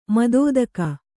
♪ madōdaka